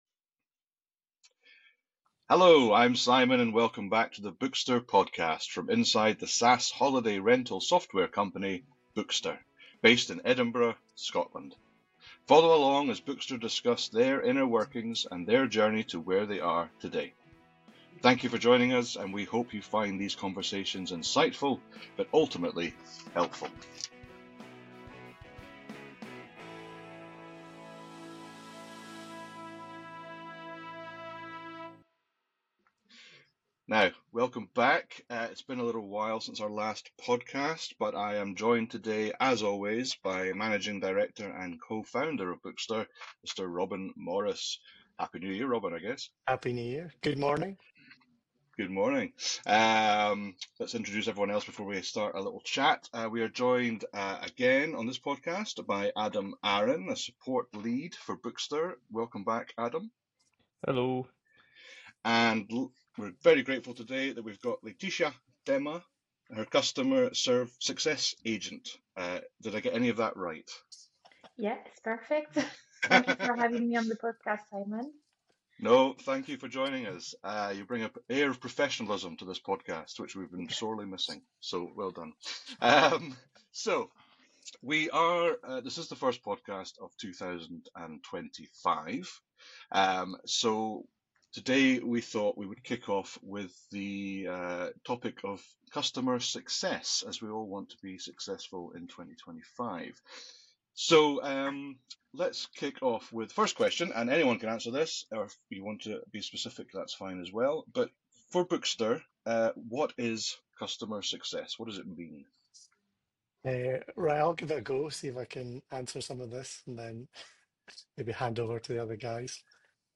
The team explains the difference between customer success (proactive support) and traditional support (reactive help), highlighting their efforts to engage clients regularly to ensure they utilize all features effectively.